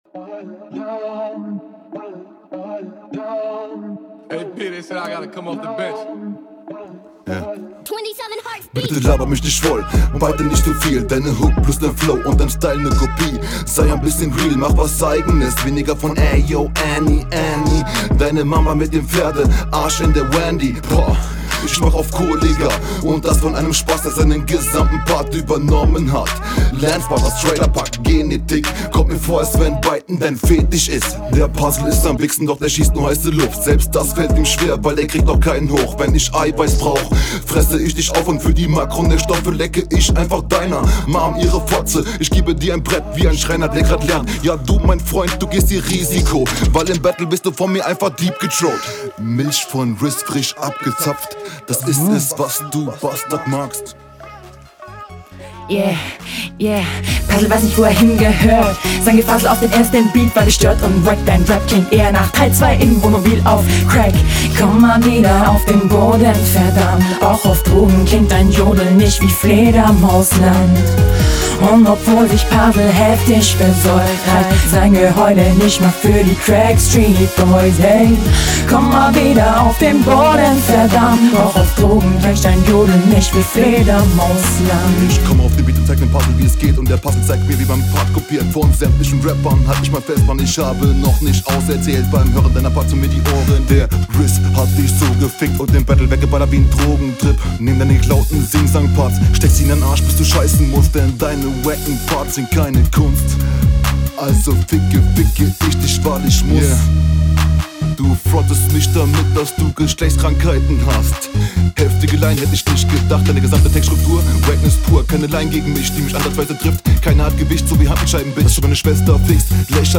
Hier bist du zu leise abgemischt und sehr oft unsynchron.